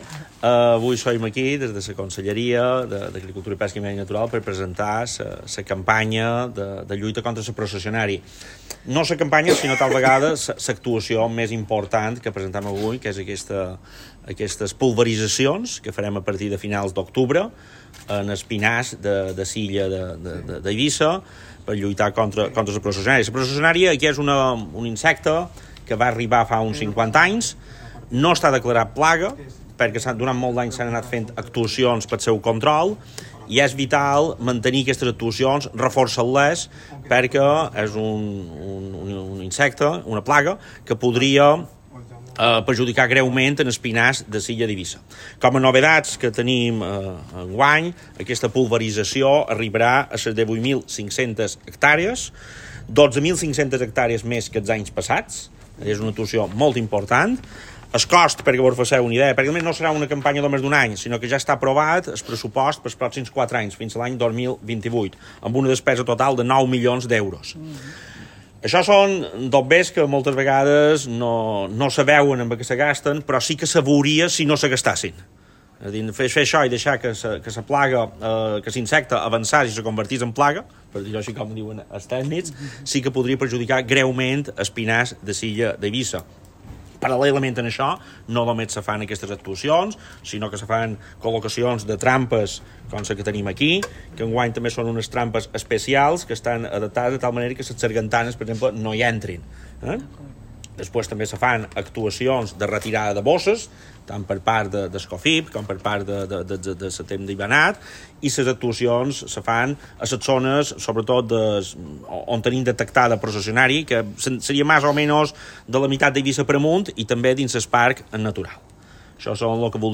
Servei de Sanitat Forestal - Entrevista Conseller - Processionària Eivissa